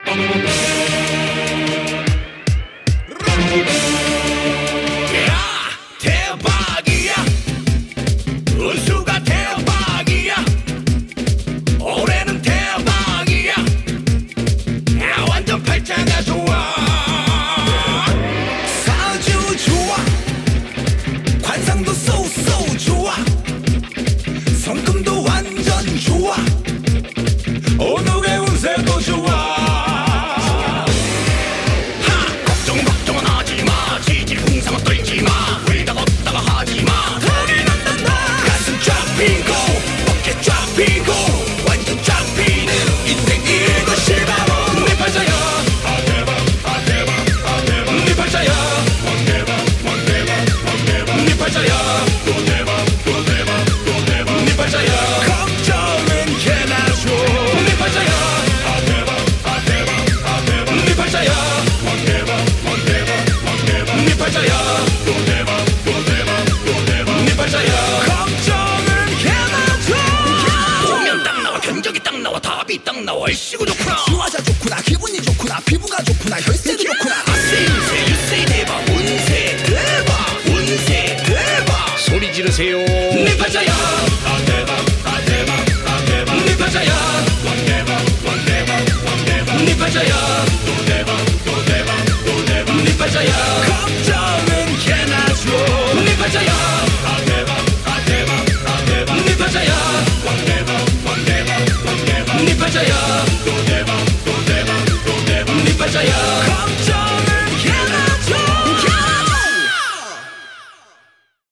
BPM150
Audio QualityPerfect (High Quality)
コメント[K-ELECTROROCK]